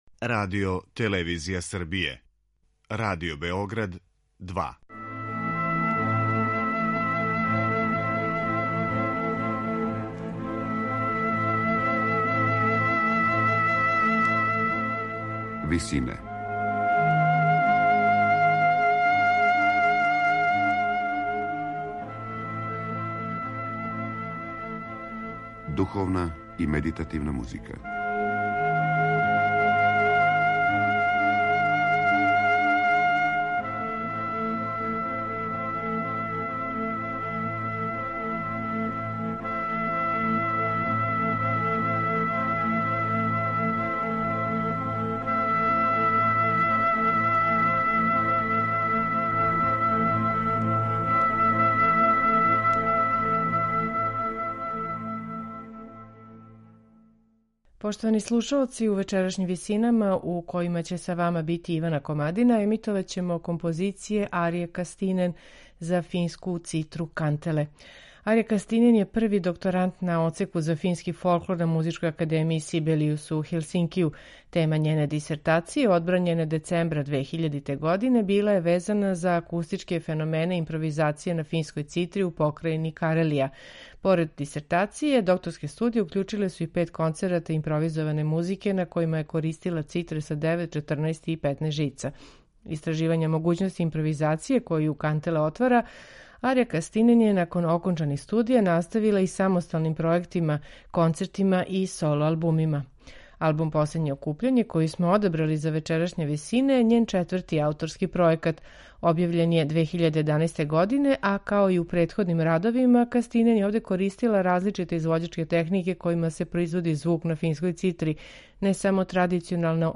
импровизације на традиционалној финској цитри кантеле